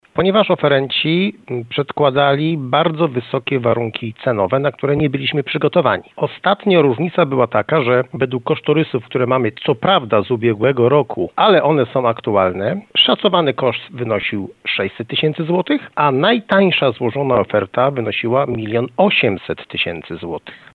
– Opóźnienie wynika z tego, że dotychczasowe przetargi musieliśmy unieważnić – tłumaczy wójt Jerzy Wałęga.